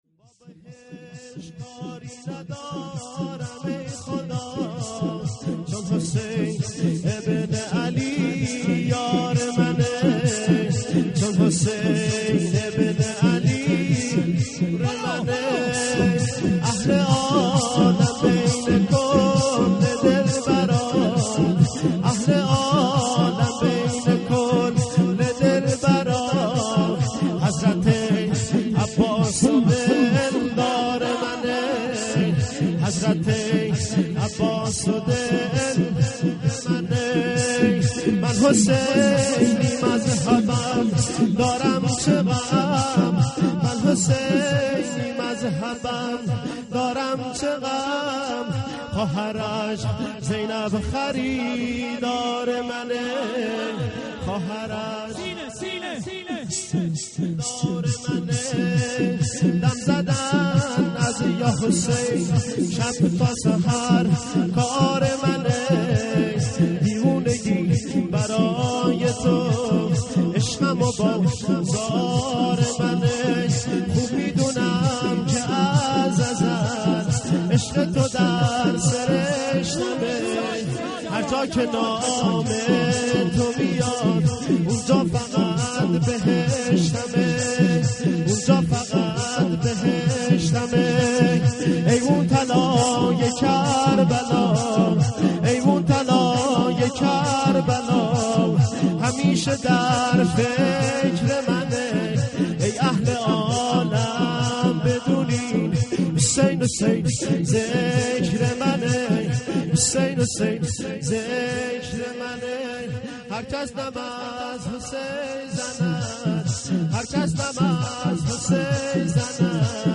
از لطف زهرا ما گرفتار حسینیم.../زمینه